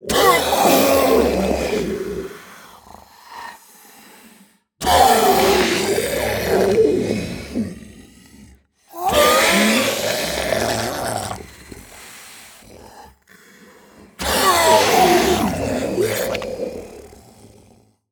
Zombie Die Sound
horror